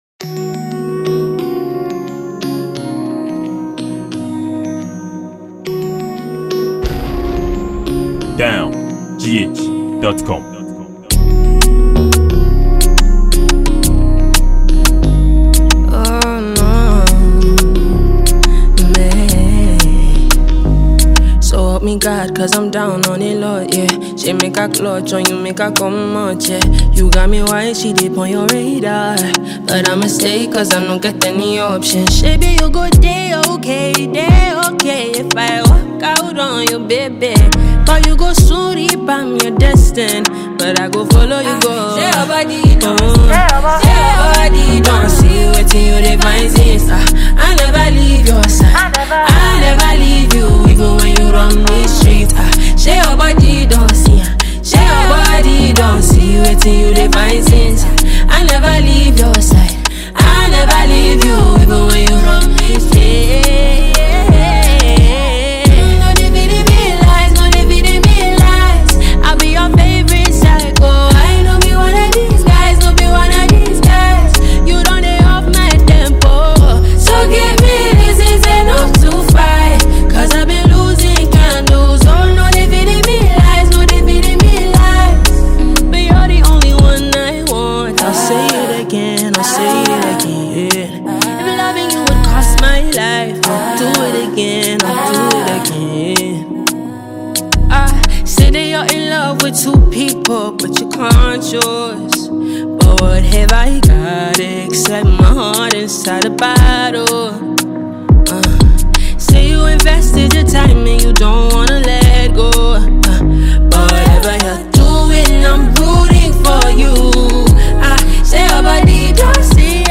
Talented Nigerian female singer